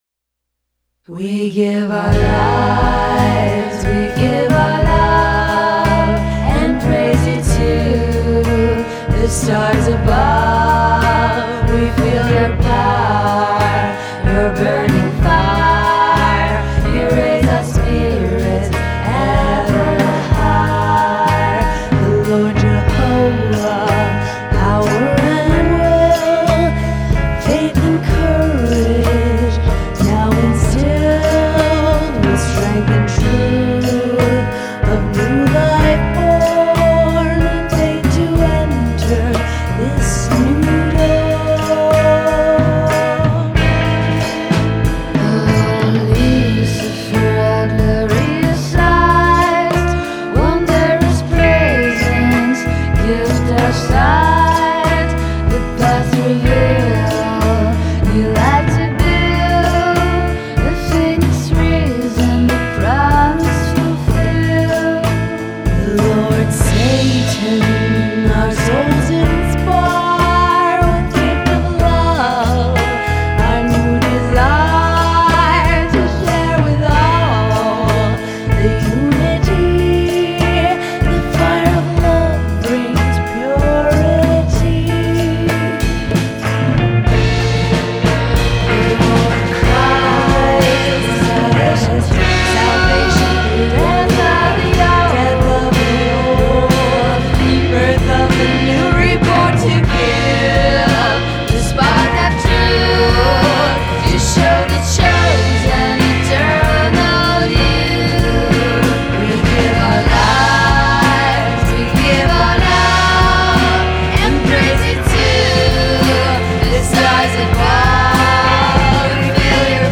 ” though hauntingly bewitching